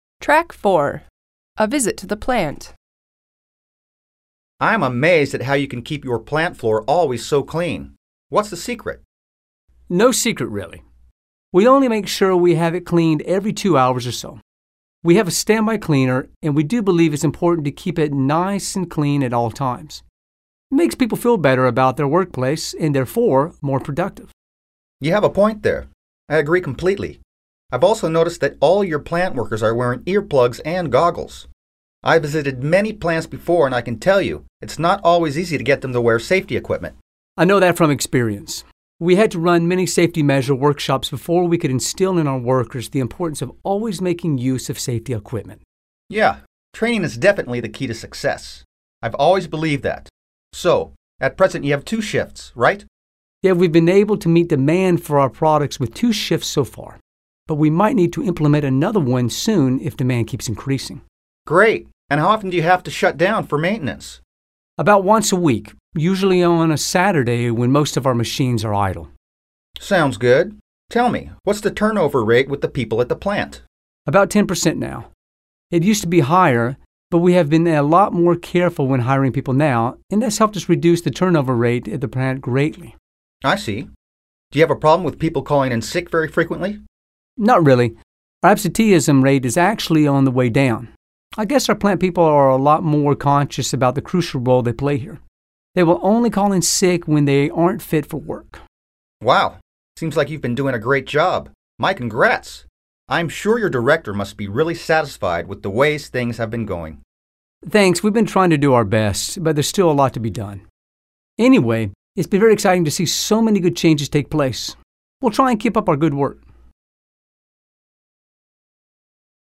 Click the link below to listen to the dialogue “A visit to the plant” and then do the activities that follow: